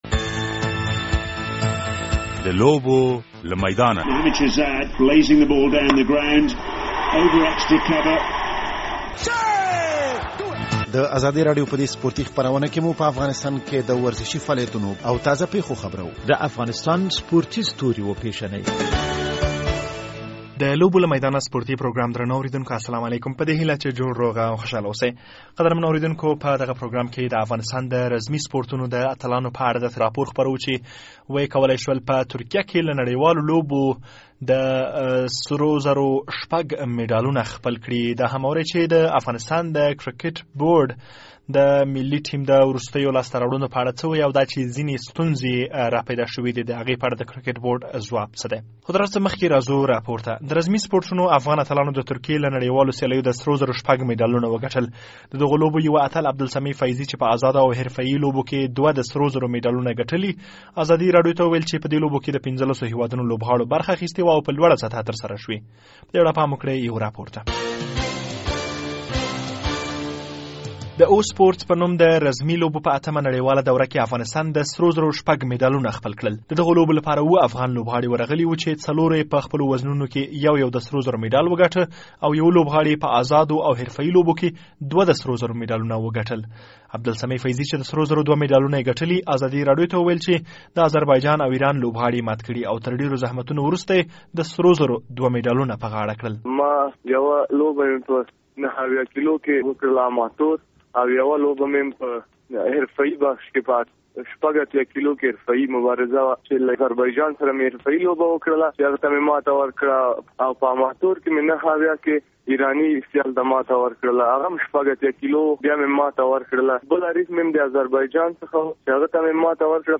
د لوبو له میدانه سپورټي پروګرام خپریدو ته چمتو دی.
په دغه پروګرام کې د رزمي سپورټونو په نړېوالو لوبو کې د افغانستان د لوبغاړو په لاسته راوړنو راپور اورئ او هم به خبر شئ چې د لویو وچو په سیالیو کې د کرکټ ملي ټیم پاپوا نیوګینې ته په ماتې ورکولو څه ترلاسه کړل او په شل اوریزو لوبو کې یې اتلولي څومره مهمه ده.